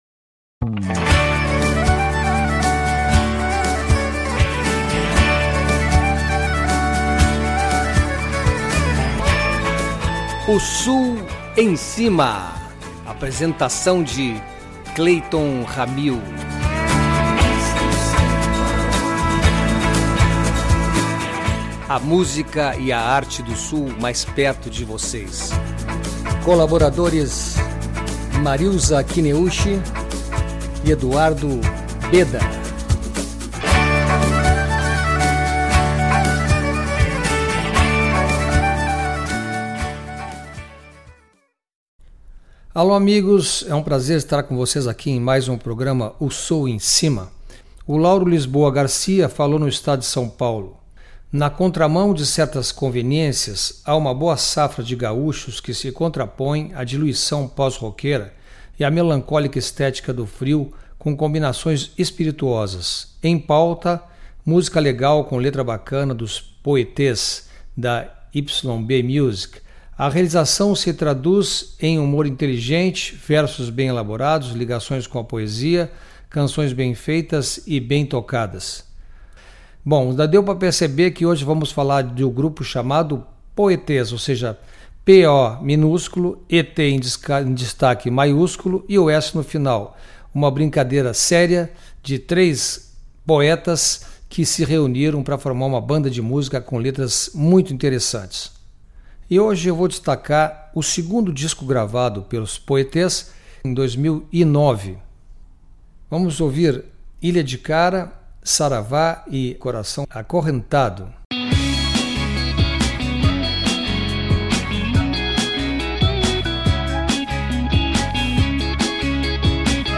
Em formato acústico - dois violões e três vozes - ou com banda - baixo, guitarra, além dos dois violões, os poETs sempre trazem um show envolvente com suas melodias contagiantes e suas letras surpreendentes.